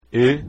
Давайте прослушаем произношение этих звуков:
ê (закрытое “e”):